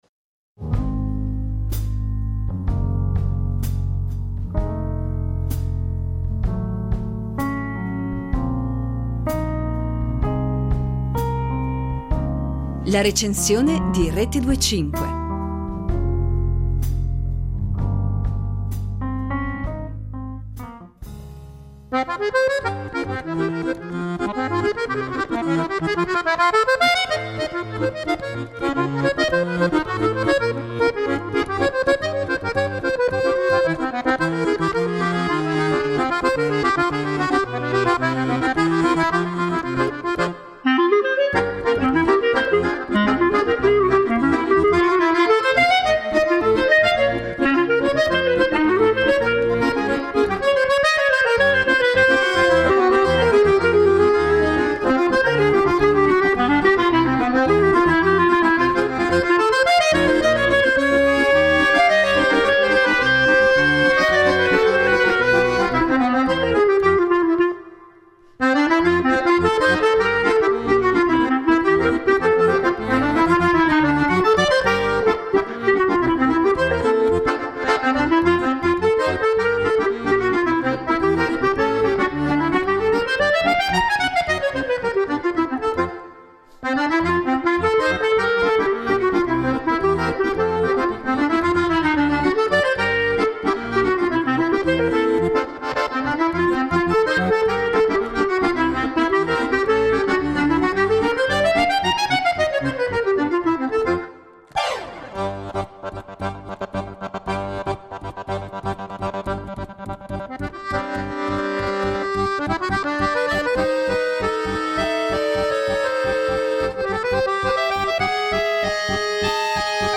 Una coppia davvero ben assortita quella formata da uno dei più grandi fisarmonicisti in circolazione e dall’altrettanto enorme talento del clarinettista Gabriele Mirabassi che si ritrovano come “Il gatto e la volpe” per regalarci il piacere pure di una musica che va dal choro alla musica popolare italiana, dal jazz alla musica da film.